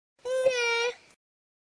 minecraft / sounds / mob / villager / no2.ogg